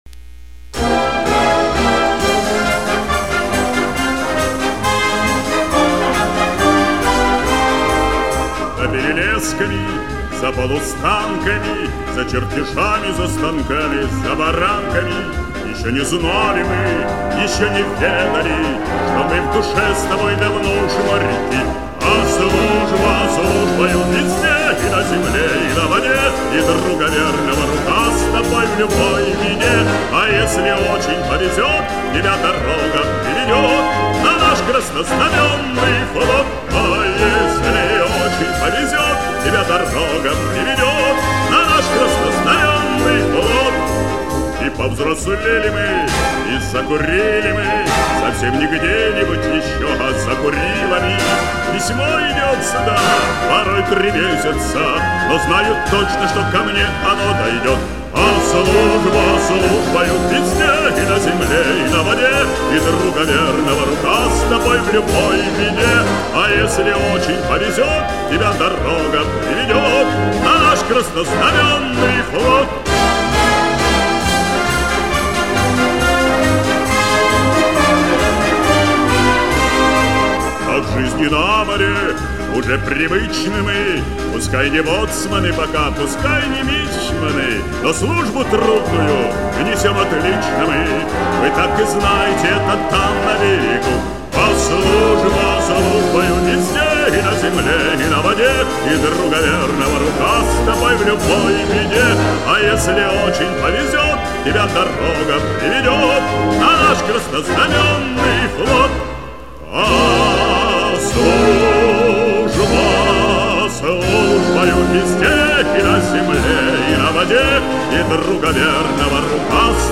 Хорошая патриотическая песня о ВМФ СССР